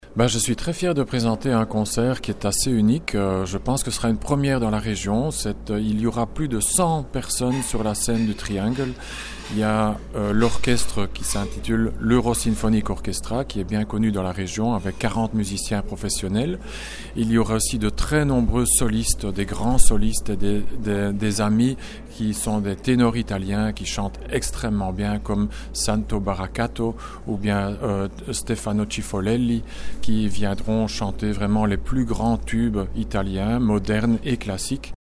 GE-Redakteur